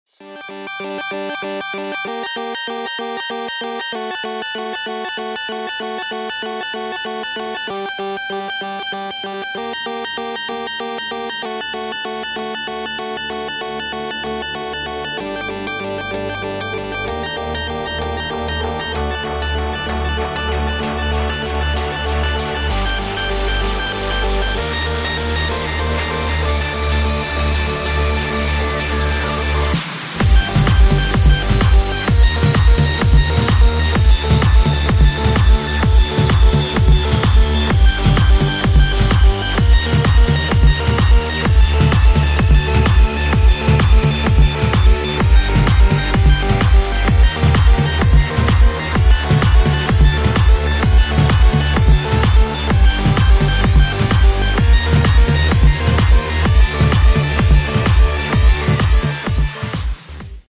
громкие
dance
Electronic
электронная музыка
без слов
progressive house